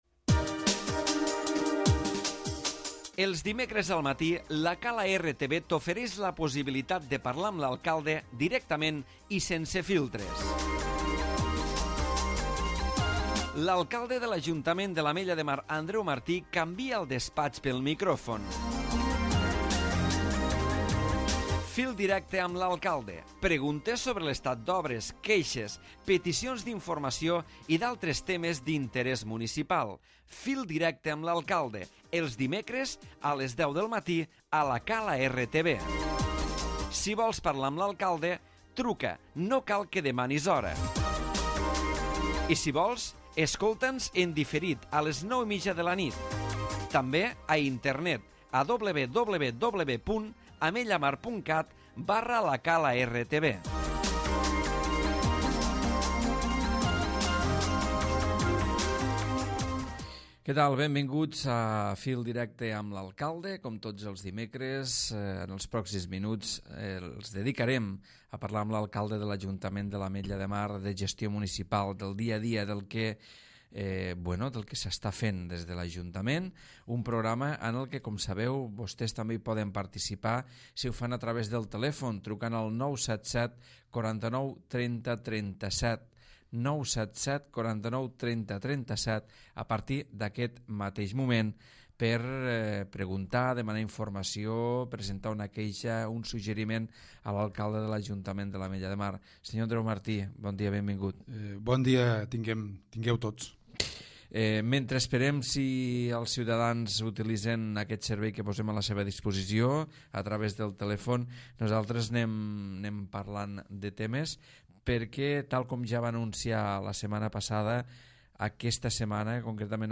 Programa adreçat als ciutadans amb telèfon obert. Avui l'Alcalde Andreu Martí ha parlat de la canalització de gas natural, del Mercadona, de la Diada de l'Arrossejat i de la comissaria dels Mossos d'Esquadra.